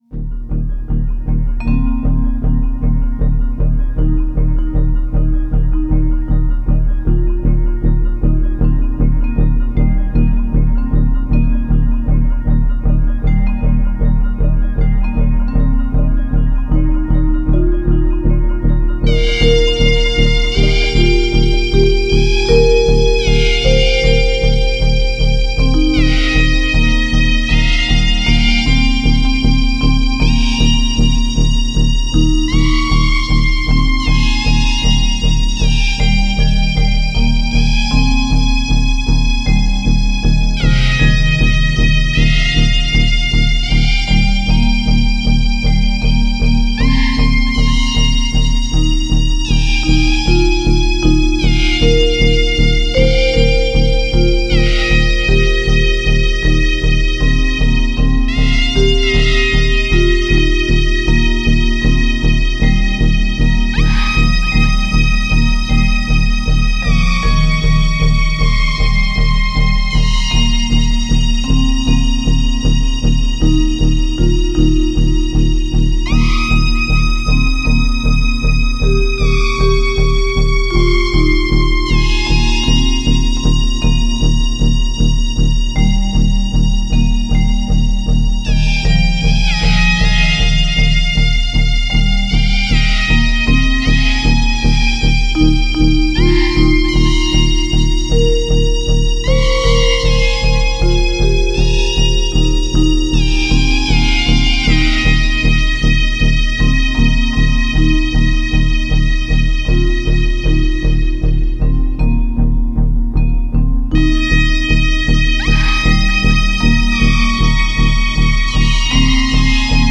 Tempo: 60 bpm / Datum: 20.05.2017